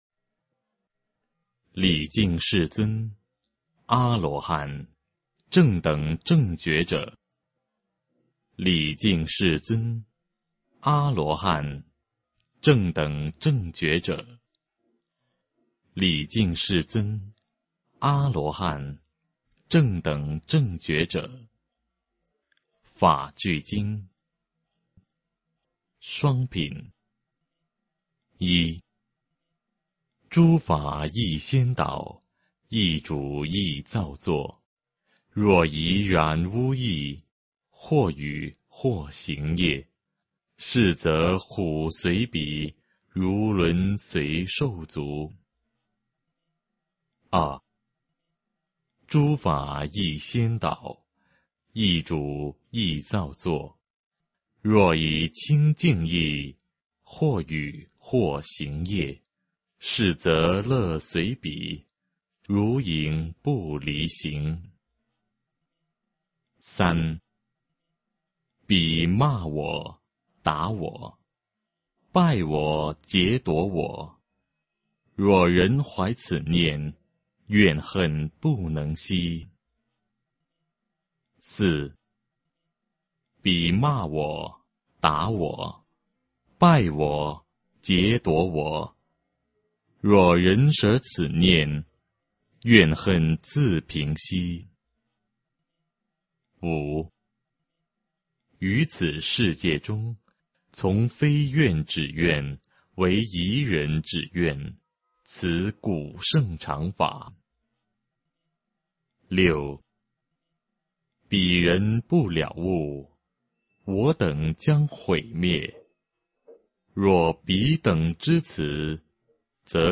法句经-双品 诵经 法句经-双品--未知 点我： 标签: 佛音 诵经 佛教音乐 返回列表 上一篇： 金光明经05 下一篇： 法句经-心品 相关文章 变亿咒 变亿咒--海涛法师...